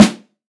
Acoustic Snare G# Key 323.wav
Royality free snare tuned to the G# note. Loudest frequency: 1340Hz
acoustic-snare-g-sharp-key-323-jyo.ogg